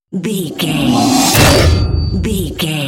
Dramatic horror metal hit
Sound Effects
Atonal
heavy
intense
dark
aggressive